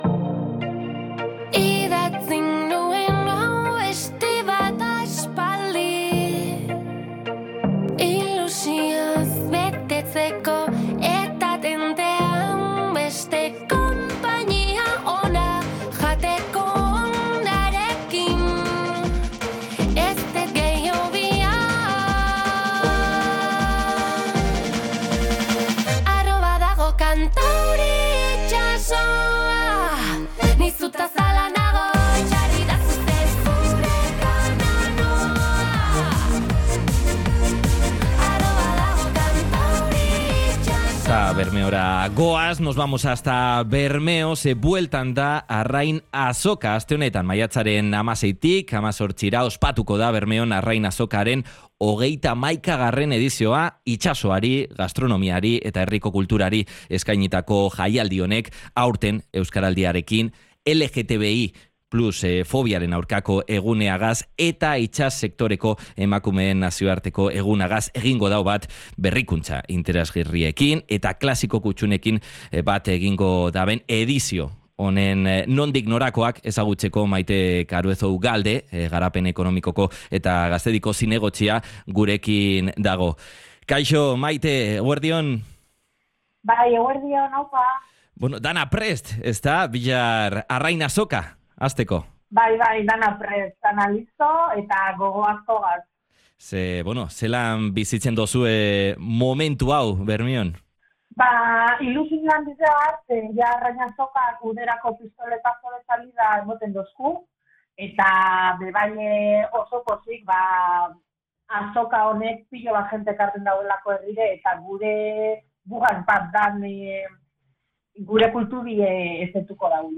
Maite Caruezo Ugalde, Garapen Ekonomikoko eta Gaztediko zinegotziak EgunOn Magazine saioan adierazi duenez, ilusioz eta gogo biziz prestatu dute aurtengo azoka: “Bermeon oso berezia da Arrain Azoka, gure kulturaren eta nortasunaren parte da”.